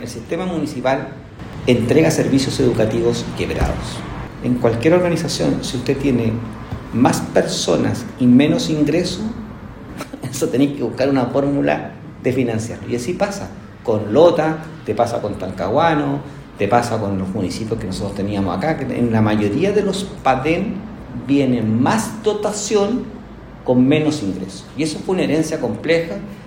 En conversación con Radio El Carbón, la autoridad se refirió a la herencia recibida por los municipios quienes por más de 40 años administraron la educación pública, donde pese a la baja de matrícula -dada la oferta educativa en los sistemas particulares y particulares subvencionados- la dotación en profesores y asistentes aumentó.